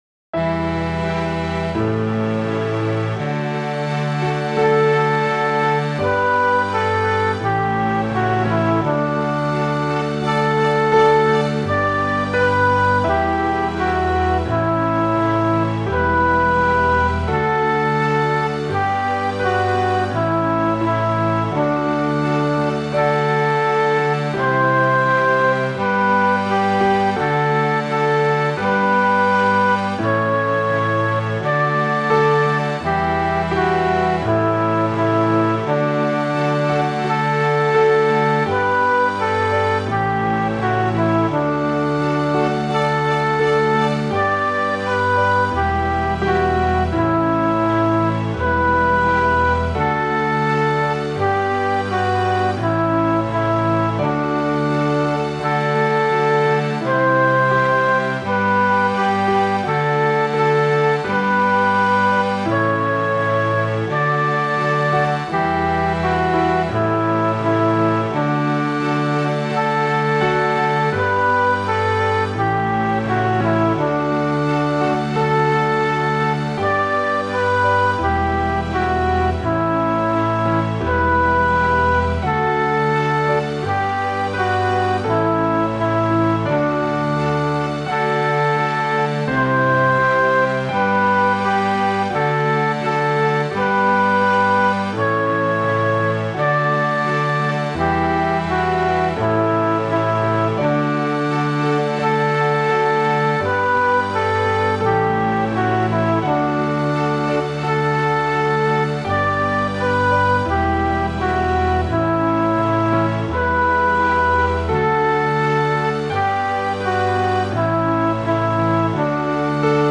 Here is a basic backing for learning purposes.